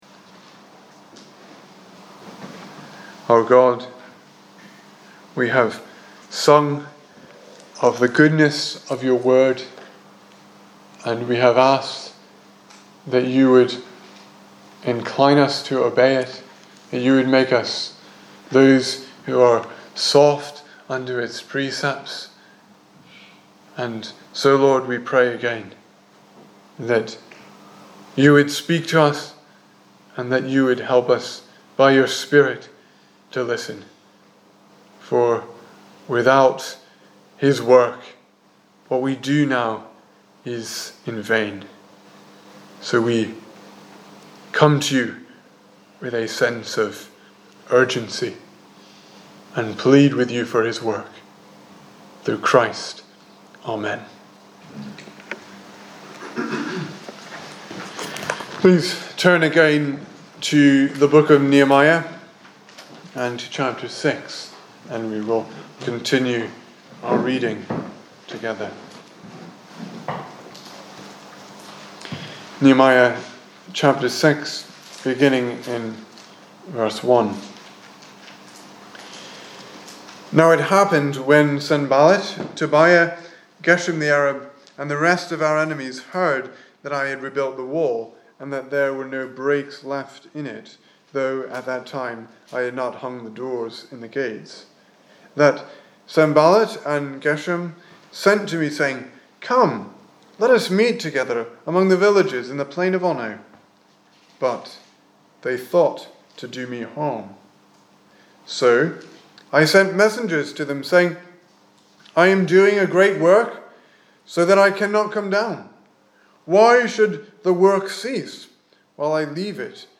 2019 Service Type: Sunday Evening Speaker